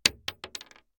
Bullet Shell Sounds
pistol_wood_2.ogg